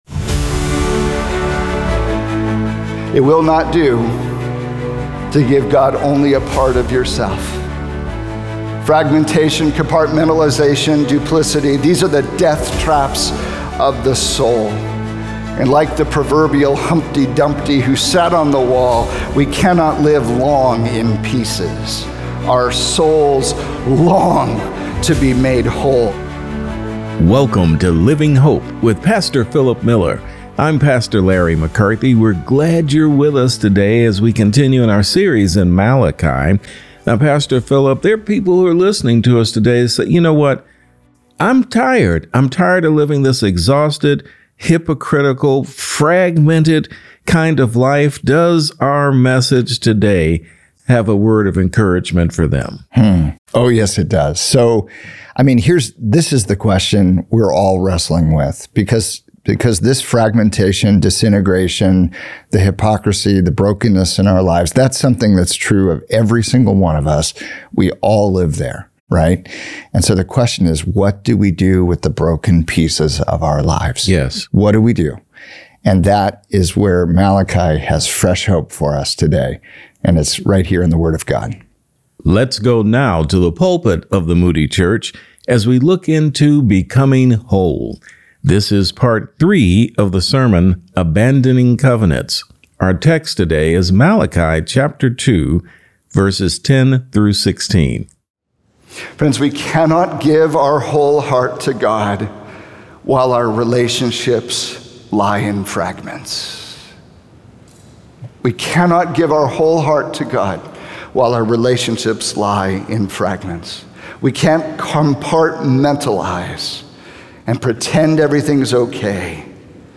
Why You Will Never Be Whole Until You Are Wholly God’s | Radio Programs | Living Hope | Moody Church Media